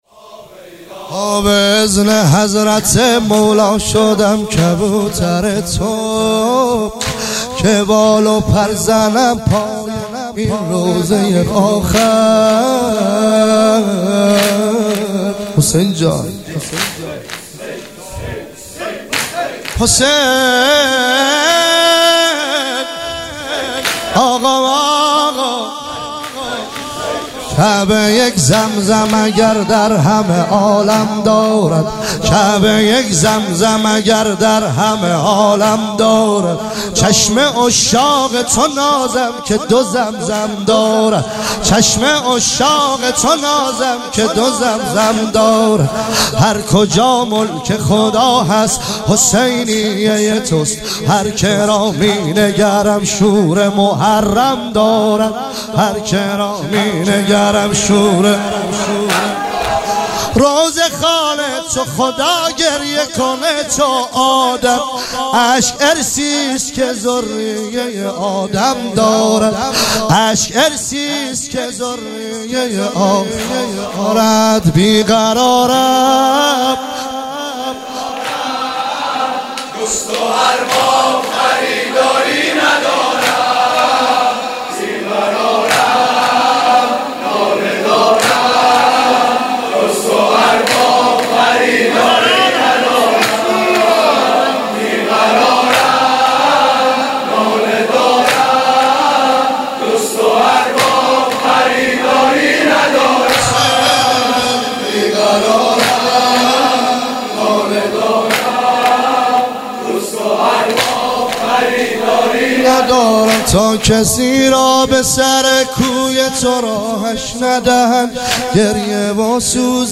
عنوان شب اول محرم الحرام ۱۳۹۸
مداح
برگزار کننده هیئت حسین جان علیه السلام گرگان
واحد تند